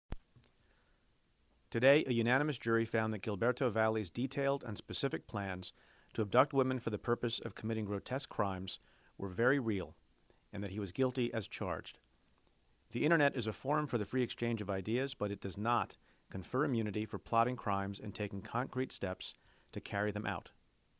Statement Of Manhattan U.S. Attorney Preet Bharara